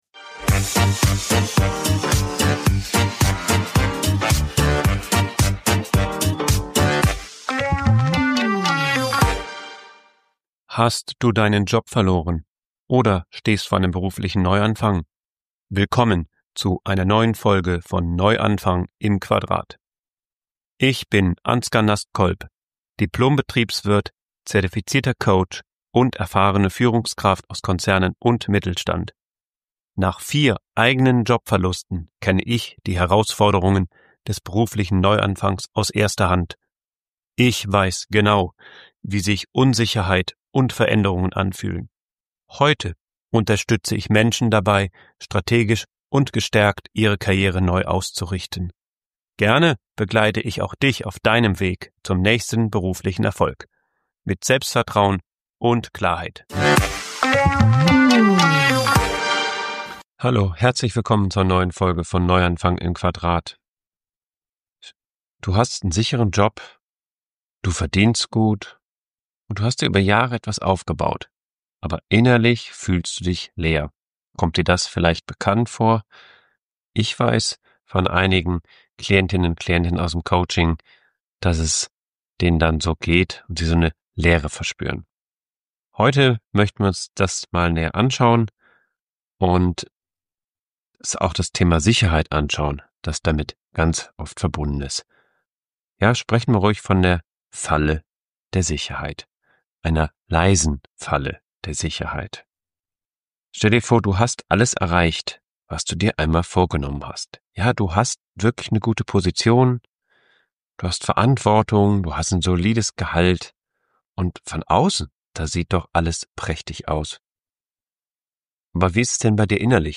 Ehrlich, ruhig und persönlich – für alle, die spüren, dass Stabilität allein nicht glücklich macht.